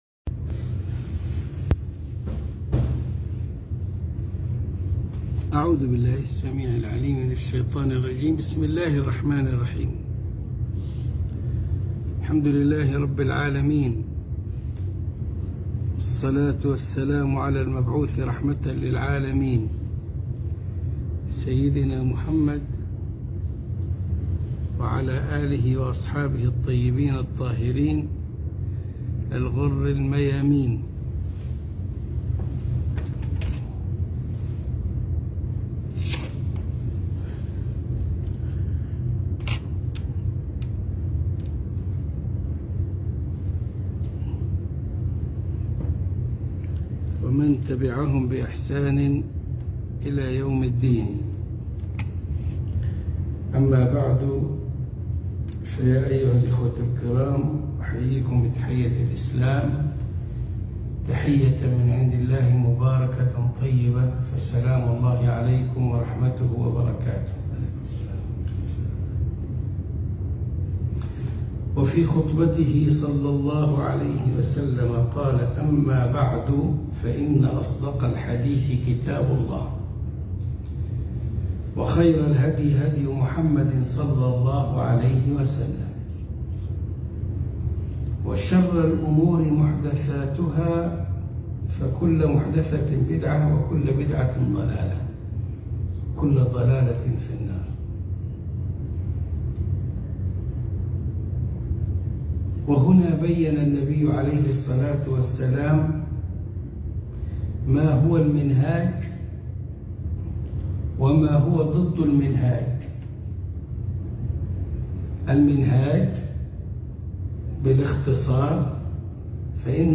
خاطرة الفجر من مسجد الروضة من مونتريالكندا